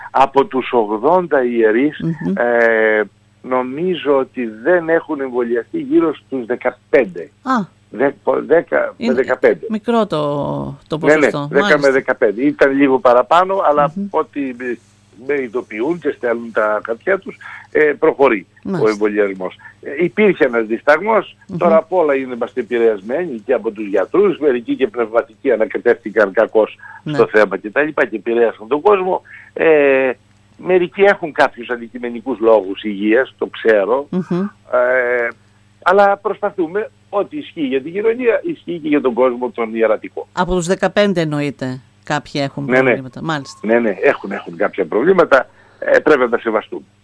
«Δεκαπέντε από τους ογδόντα ιερείς της Μητροπόλεως Αλεξανδρουπόλεως Τραϊανουπόλεως και Σαμοθράκης είναι ανεμβολίαστοι» δήλωσε σήμερα στην ΕΡΤ Ορεστιάδας ο Μητροπολίτης κ. Άνθιμος.